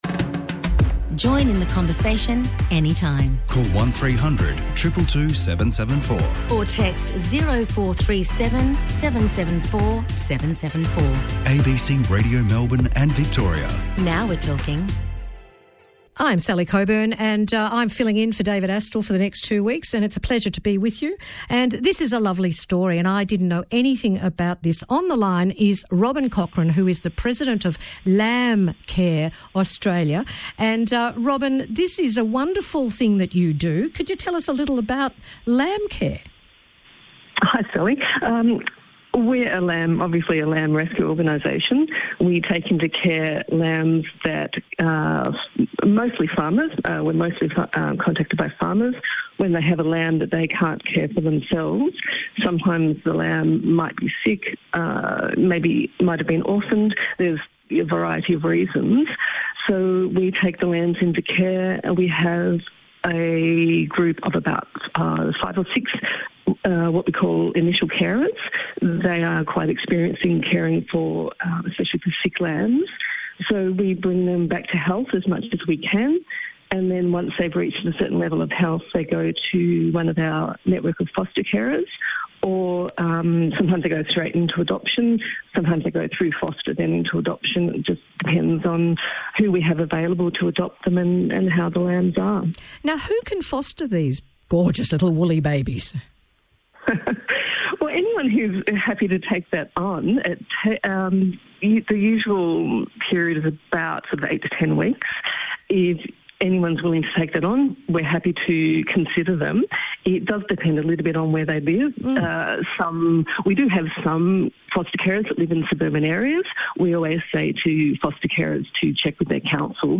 Lambcare-Australia-interview.mp3